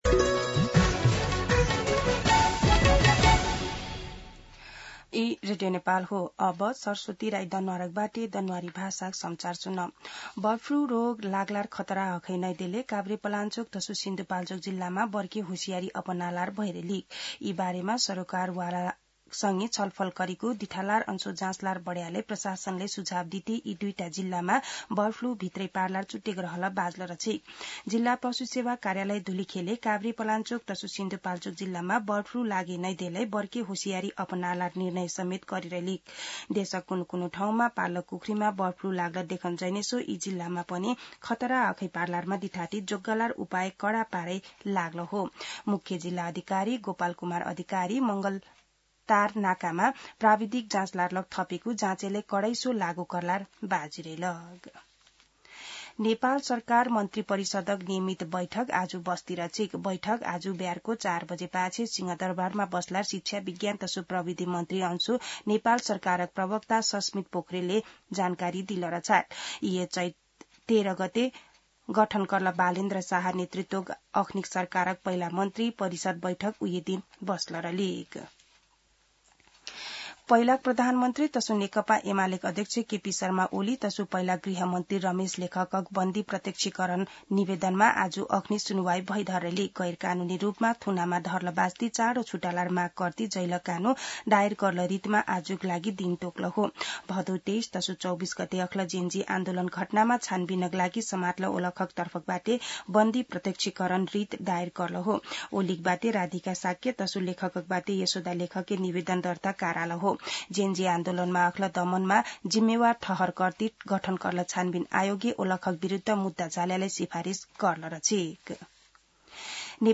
दनुवार भाषामा समाचार : १६ चैत , २०८२
Danuwar-News-16.mp3